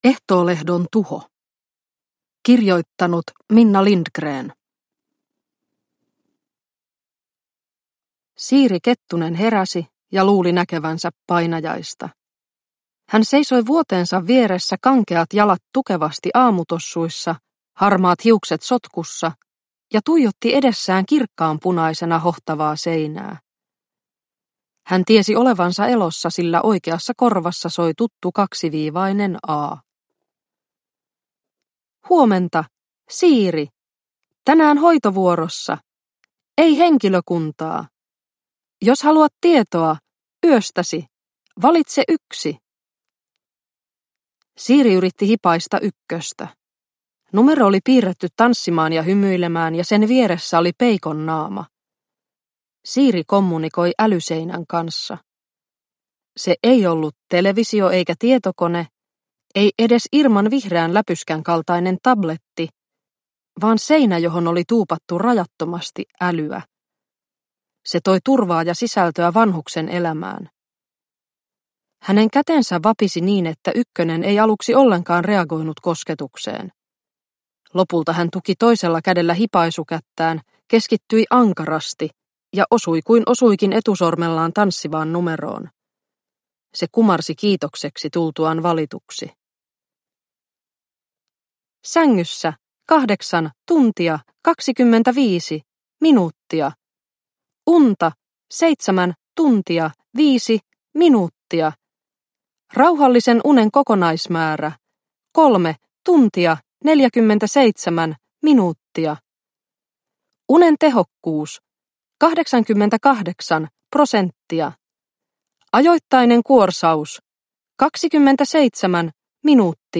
Ehtoolehdon tuho – Ljudbok – Laddas ner